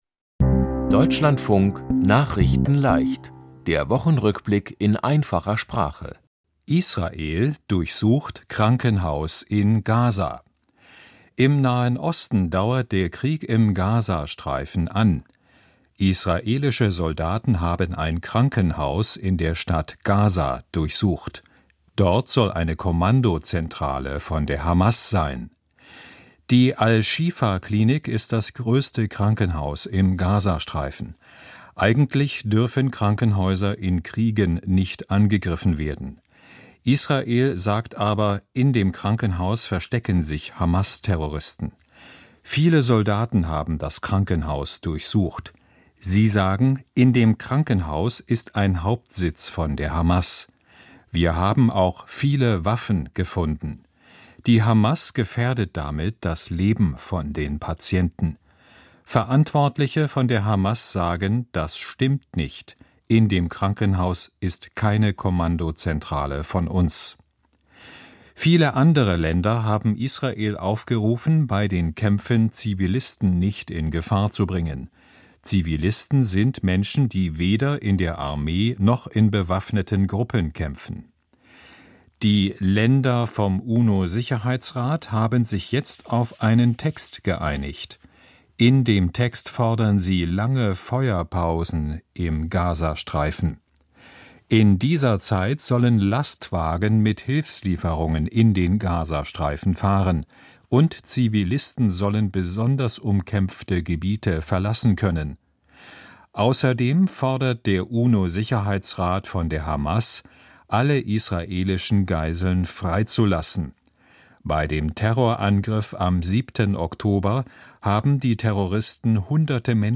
Der Wochen-Rückblick in Einfacher Sprache